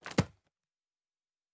Fantasy Interface Sounds